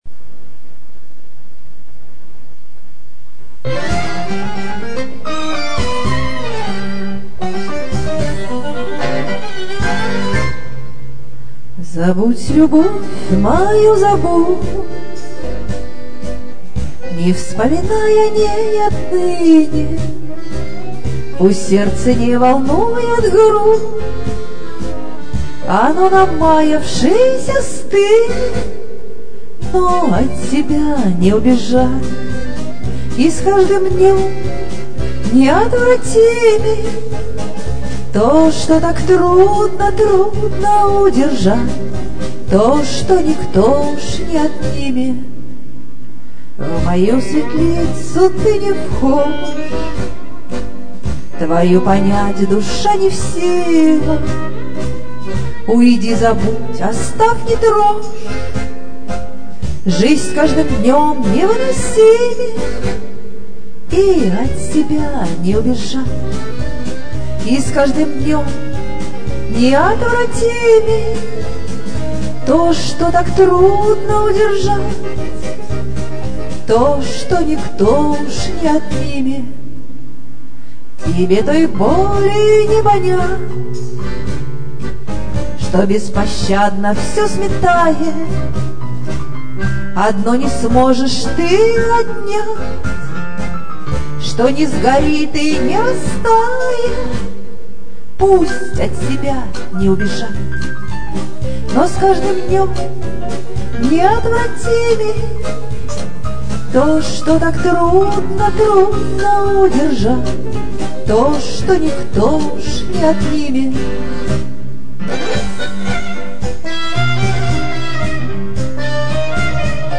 И с каждым днём неотвратимей (танго)
моя авторская песня